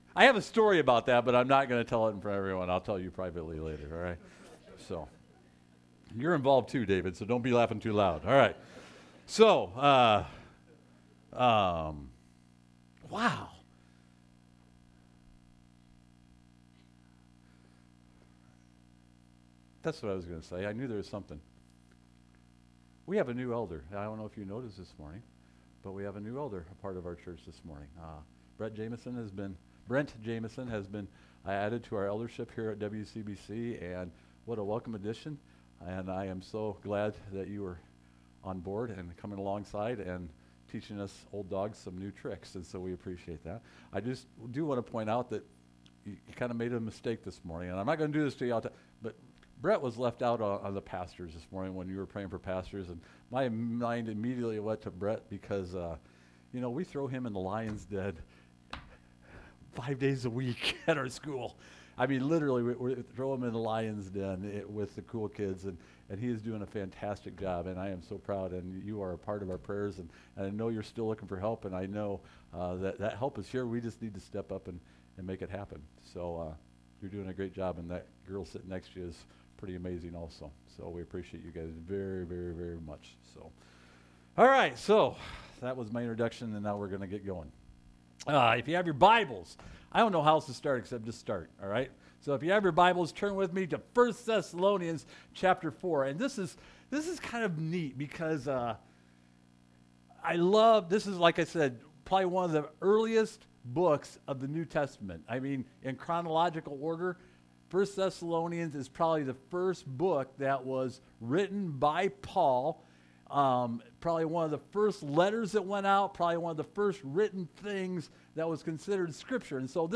Matthew 5:27-48 Service Type: Sunday Morning Are you looking at others for validation?